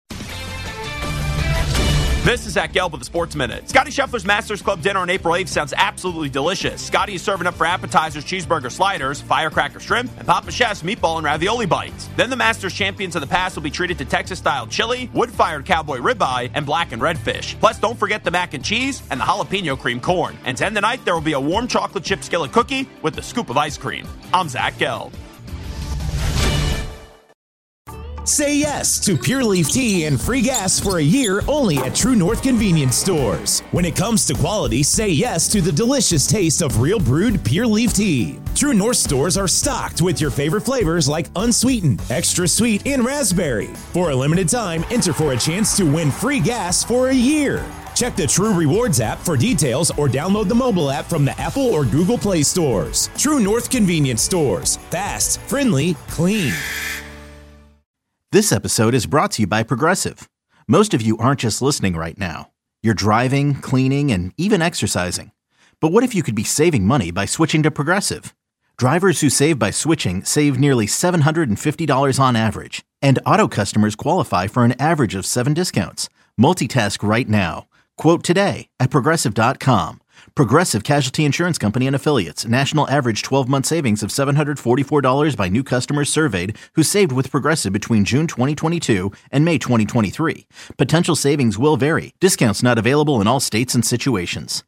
Hourly Commentaries between 6am-7pm by Infinity Sports Network talent